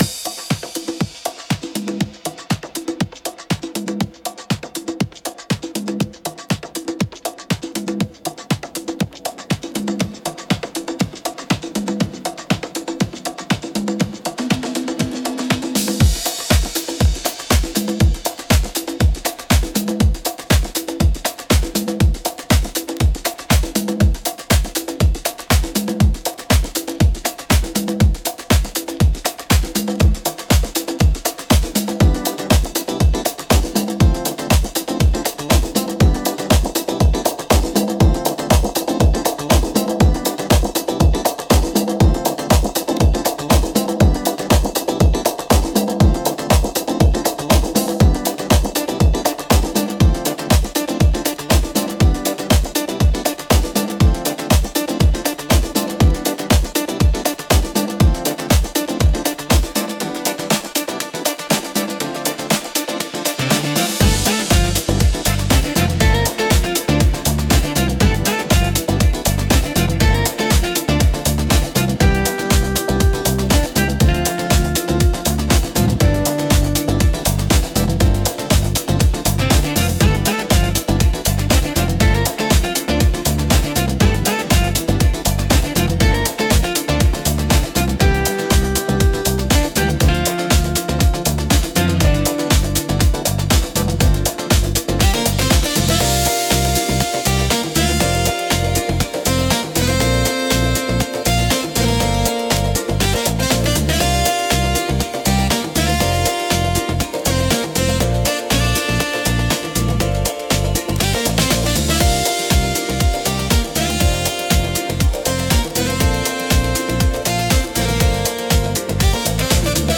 特に、ポジティブで元気な印象を与えたい時に適しています。